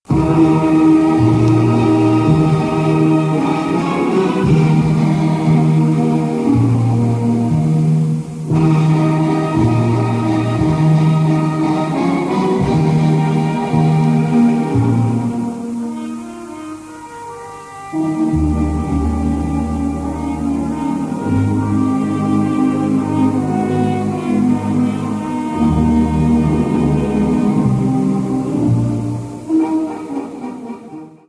Gattung: Moderne Rhapsodie
Besetzung: Blasorchester